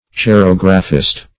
Cerographist \Ce*rog"ra*phist\, n. One who practices cerography.